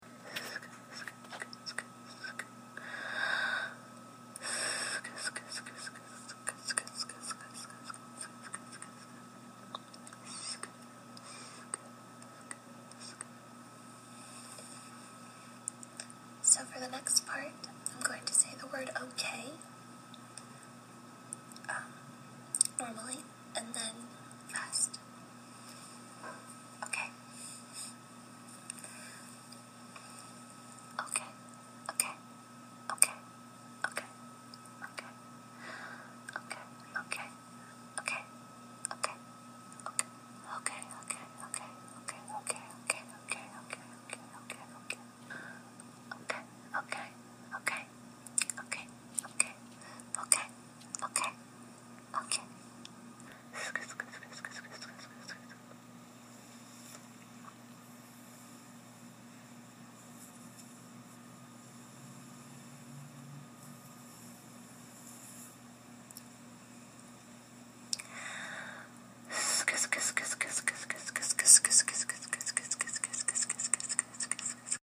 The ASM-artist (as creators of ASMR triggering sounds are affectionately called) is first “SK’ing,” creating a very clicky sound with the two letters over and over, and then saying “okay” over and over to the same end. She briefly introduces the second sound in the middle. She is whispering and barely audible, as the video is meant to be viewed while wearing headphones, for maximum relaxation and triggering. In the original post of this audio, the sound can be hear binaurally, as she recorded with special microphones.